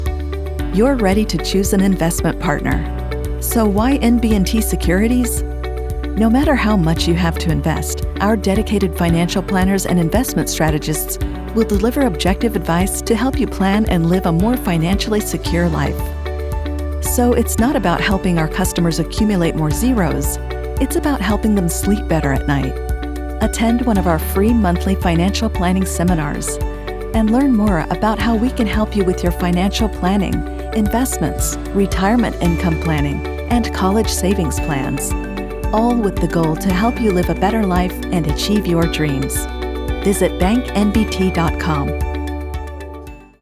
Engels (Amerikaans)
Commercieel, Natuurlijk, Vertrouwd, Vriendelijk, Warm
Corporate